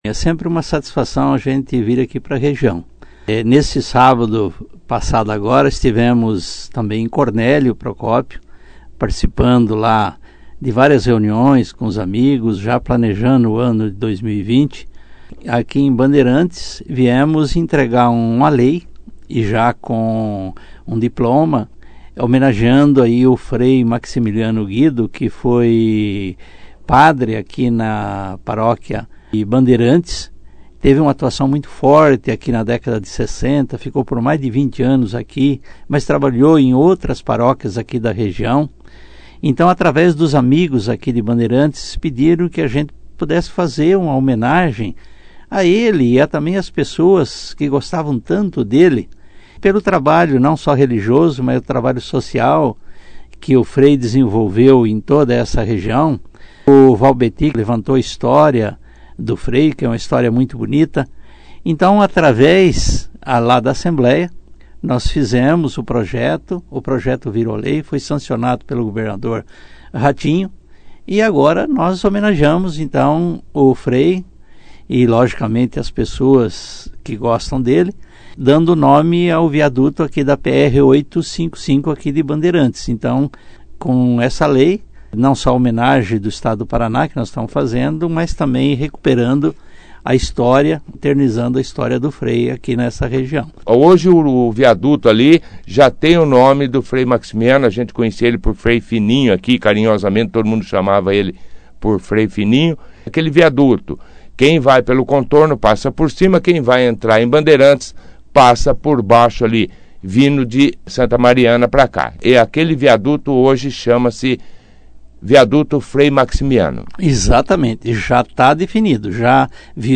Em matéria levada ao ar na 2ª edição do jornal Operação Cidade desta segunda-feira, 13/01/20, o deputado falou sobre a homenagem ao Frei e da visita a correligionários na cidade, além de lançar o nome do ex-vereador Valbeti Palugan, como pré-candidato a prefeito nas eleições de outubro pelo seu partido o Cidadania.